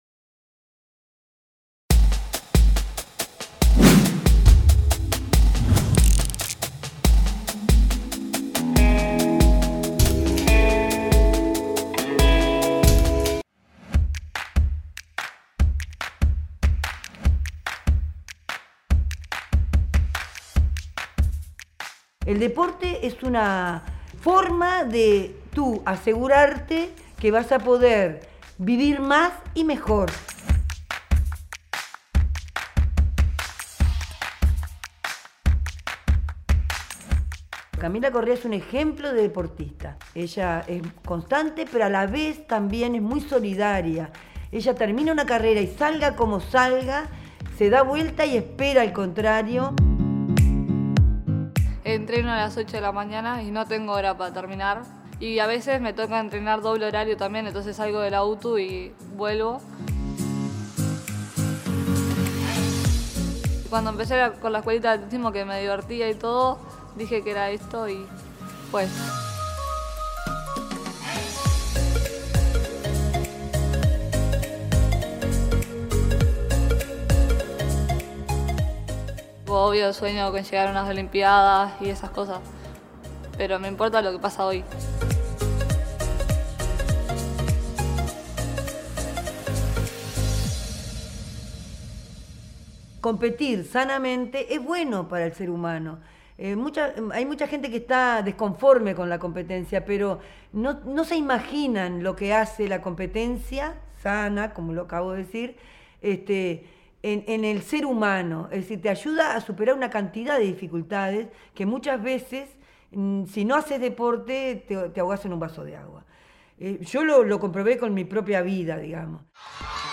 En transmisión simultánea de radio y televisión, se emitió el informe temático “Deporte en Uruguay”, que muestra testimonios de deportistas de alta competencia y personas que realizan ejercicio físico para mejorar su calidad de vida, como los 3.800 vecinos del Cerro en la plaza de deporte n.° 11.